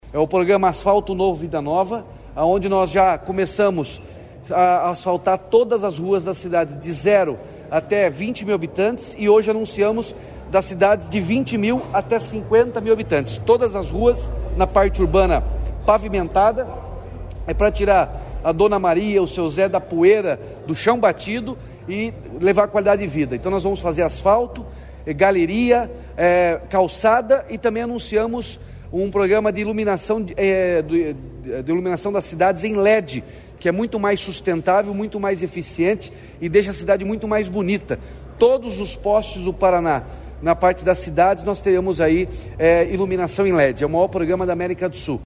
Sonora do governador Ratinho Junior sobre o lançamento de novas fases do Asfalto Novo, Vida Nova